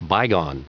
Prononciation du mot bygone en anglais (fichier audio)
Prononciation du mot : bygone